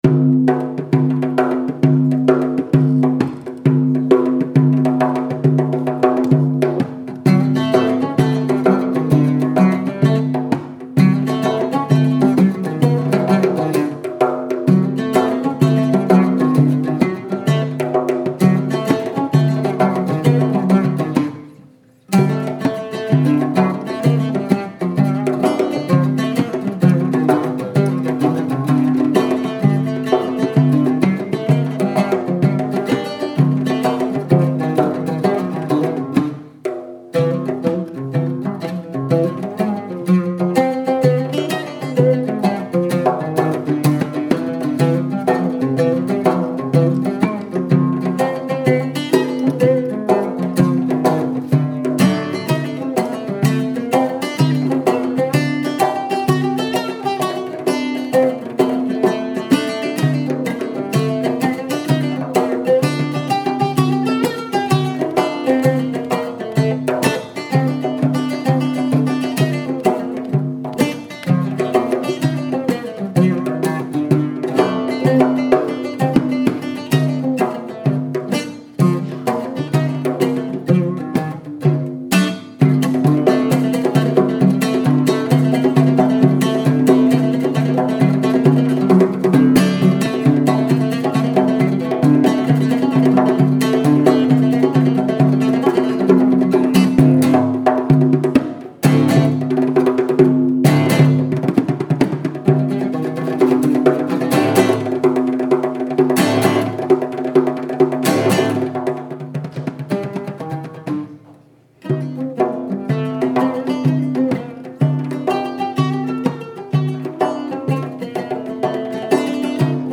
Woodstock VT